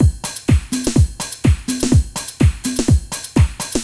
Index of /musicradar/retro-house-samples/Drum Loops
Beat 15 Full (125BPM).wav